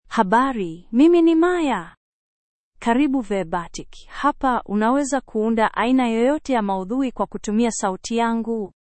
Maya — Female Swahili (Kenya) AI Voice | TTS, Voice Cloning & Video | Verbatik AI
Maya is a female AI voice for Swahili (Kenya).
Voice sample
Listen to Maya's female Swahili voice.
Female
Maya delivers clear pronunciation with authentic Kenya Swahili intonation, making your content sound professionally produced.